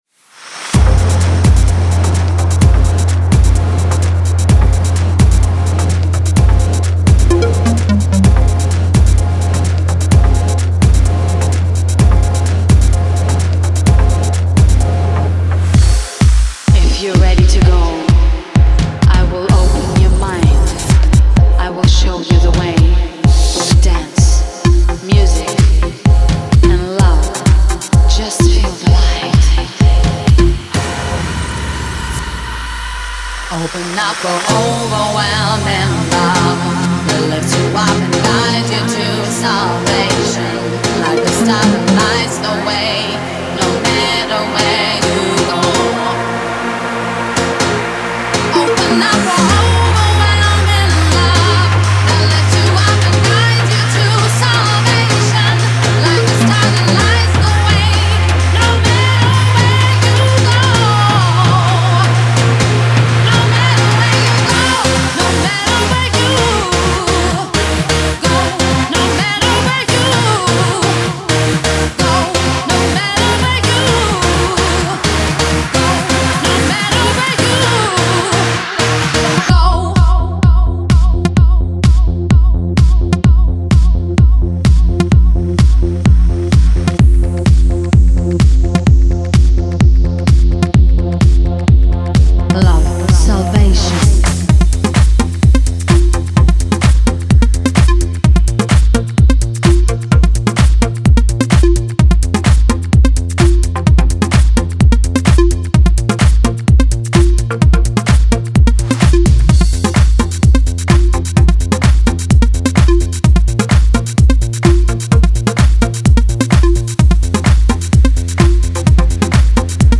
Жанр: Только качественная POP музыка